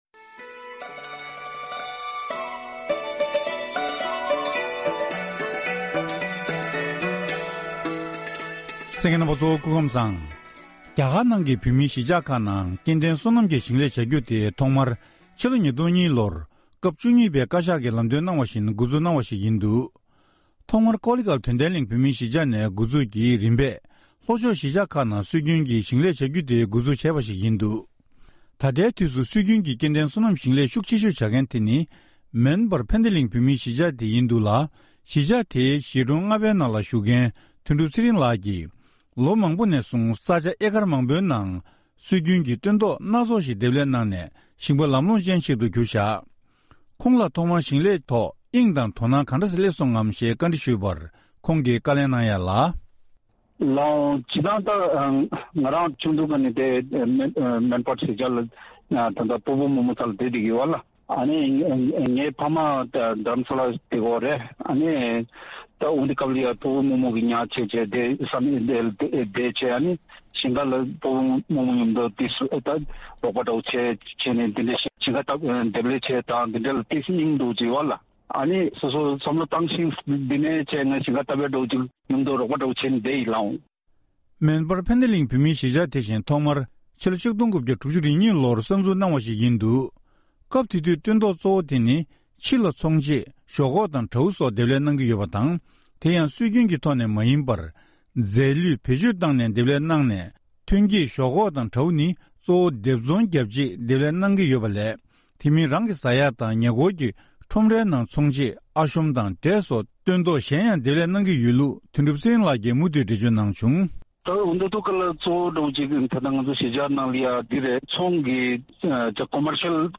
བཀའ་འདྲི་ཞུས་ནས་ཕྱོགས་སྒྲིགས་ཞུས་པ་ཞིག་